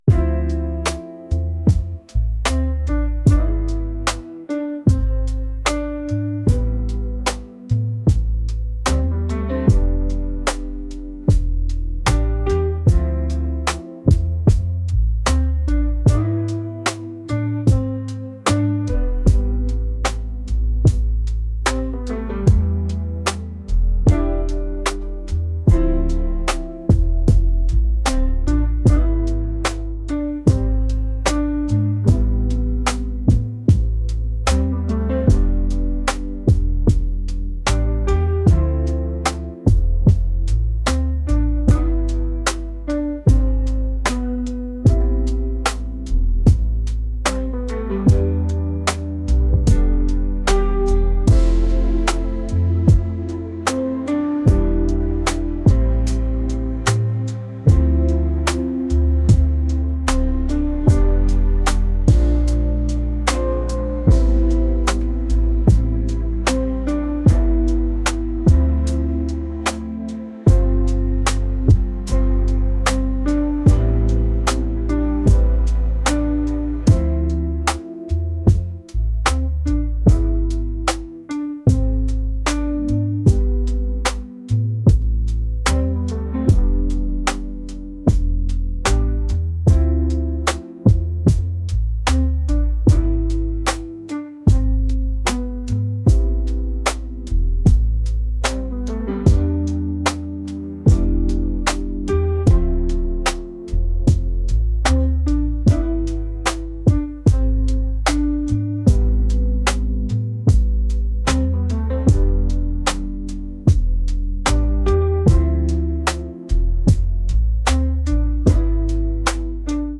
soul & rnb | soulful | retro